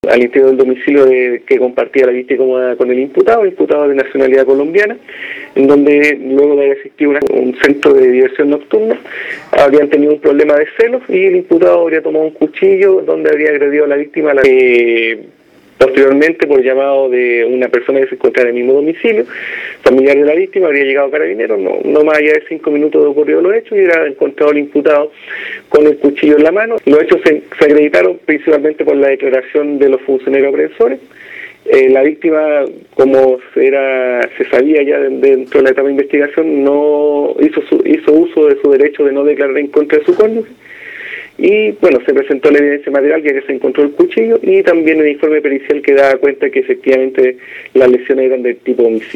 Escuche al fiscal Juan Pablo Aguilera.